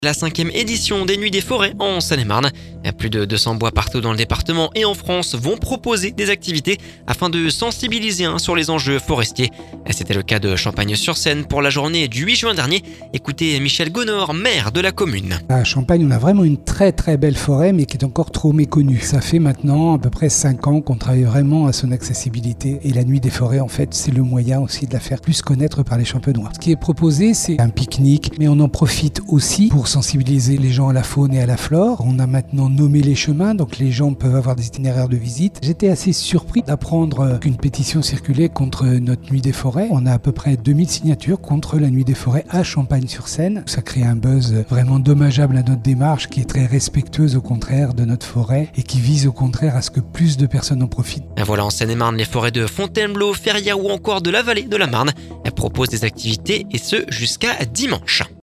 Michel Gonord, maire de la commune…